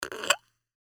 Gemafreie Sounds: Restaurant und Cafe
mf_SE-4112-opening_a_beer_bottle_3.mp3